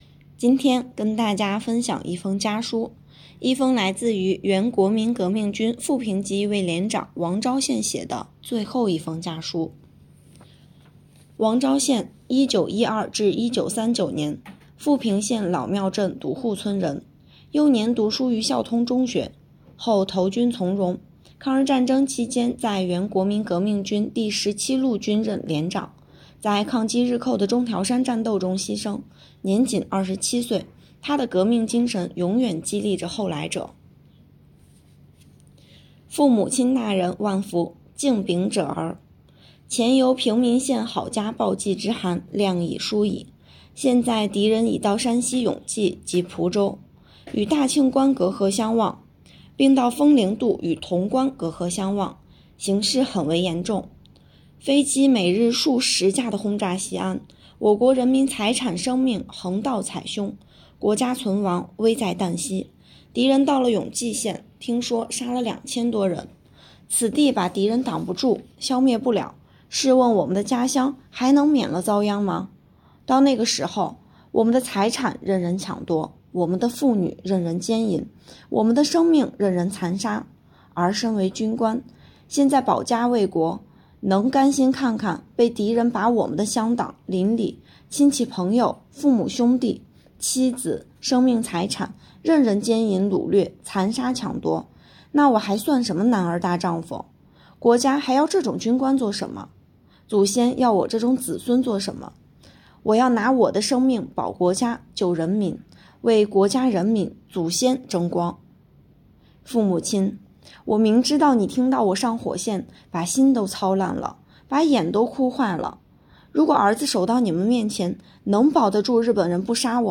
【红色档案诵读展播】抗日烈士王昭宪的一封家书